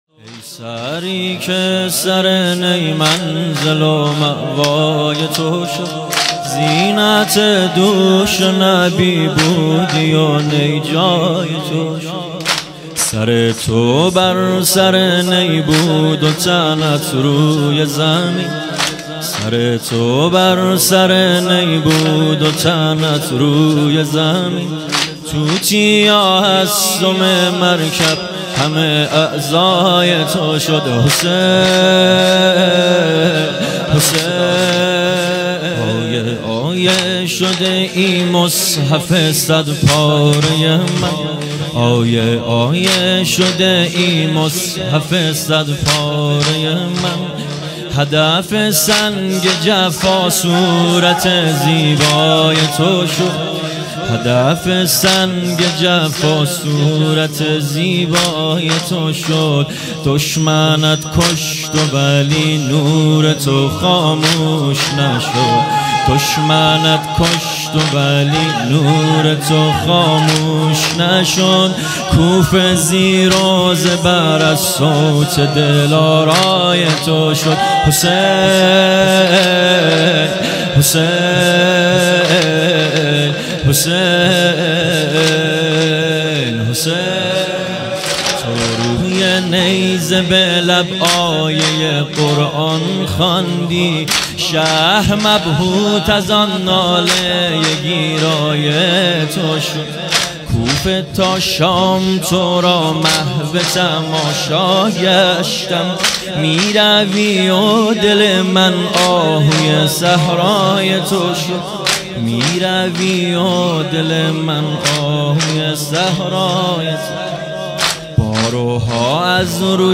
سالگرد تخریب بقیع هیئت جنت العباس (ع) کاشان